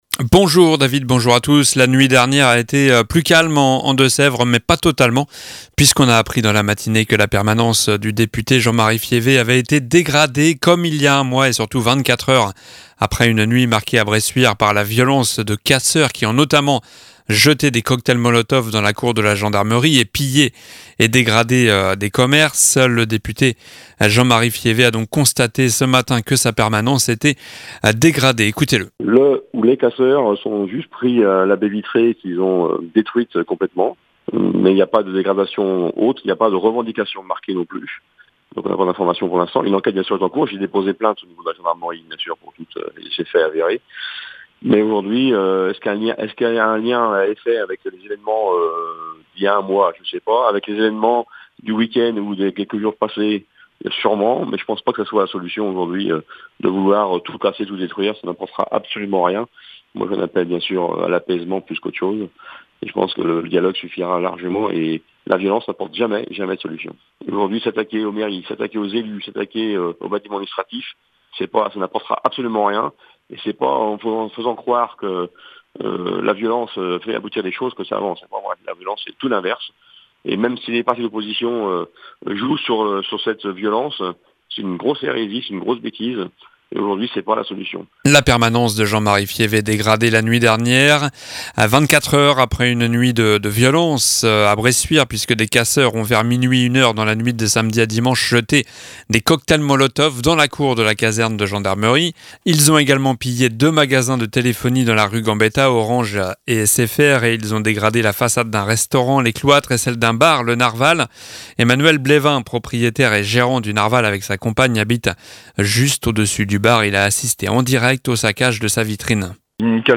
Journal du lundi 03 juillet (midi)